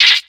Cri de Nénupiot dans Pokémon X et Y.